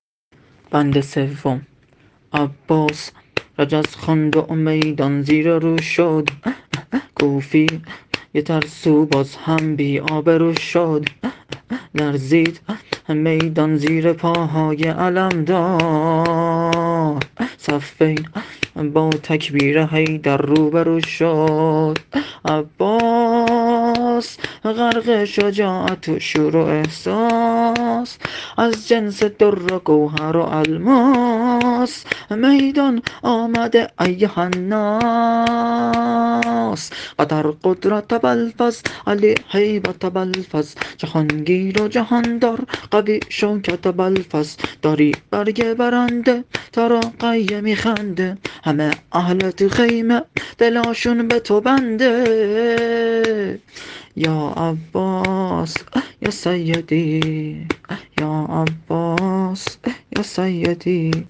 شور حضرت علی (ع) -(احمد رو کرد به حیدر برو میدون)